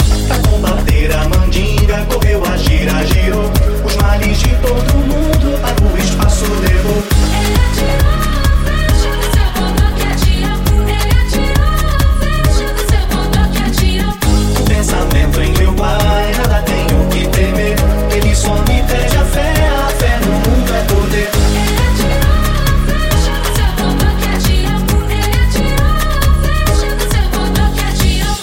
Genere: pop,dance,afrobeat,house.remixhit